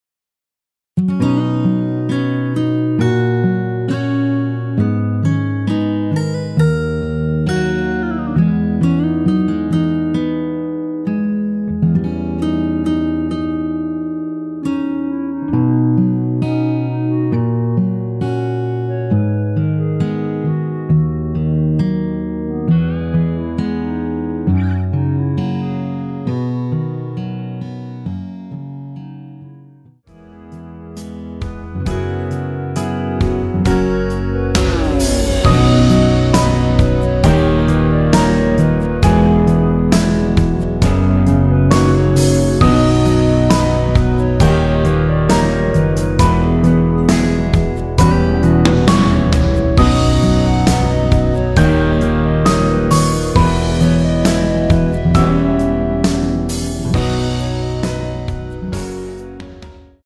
원키에서(-1) 내린 멜로디 포함된 MR 입니다.
노래방에서 노래를 부르실때 노래 부분에 가이드 멜로디가 따라 나와서
앞부분30초, 뒷부분30초씩 편집해서 올려 드리고 있습니다.
중간에 음이 끈어지고 다시 나오는 이유는